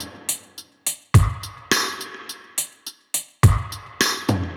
Index of /musicradar/dub-drums-samples/105bpm
Db_DrumKitC_Wet_105-02.wav